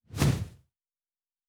Wing Flap 4_10.wav